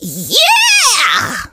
meg_kill_vo_03.ogg